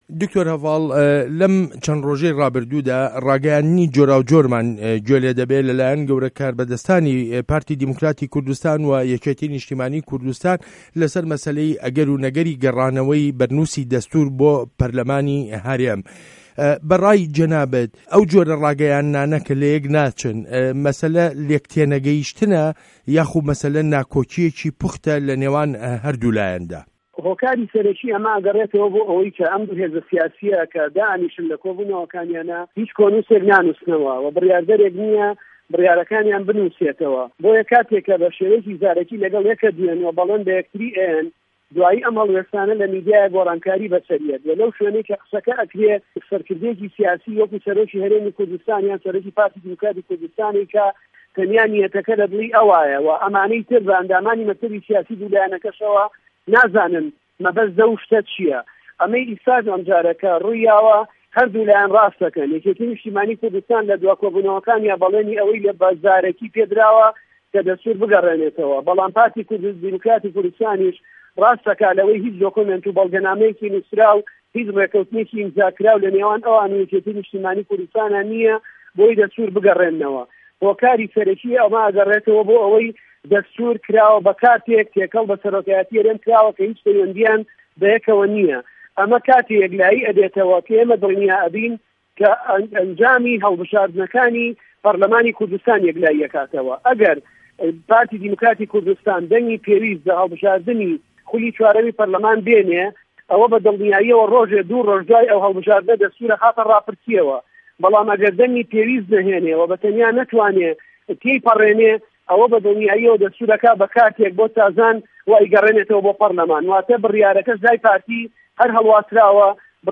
وتووێژ له‌گه‌ڵ دکتۆر هه‌ڤاڵ ئه‌بوبه‌کر